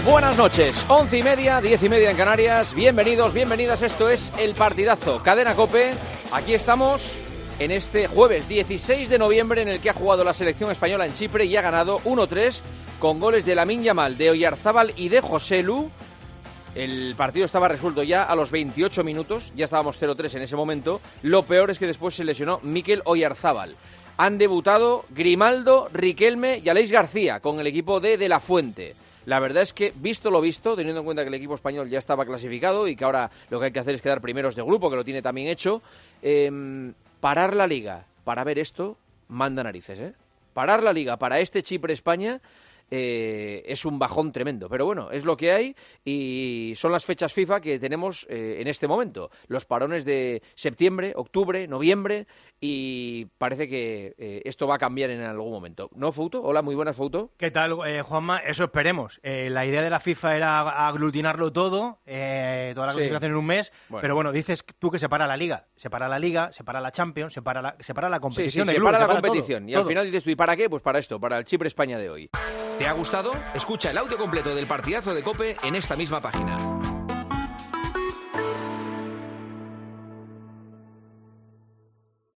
El director y presentador de El Partidazo de COPE comenzó el programa de este jueves hablando del Chipre-España, disputado esta tarde en territorio chipriota.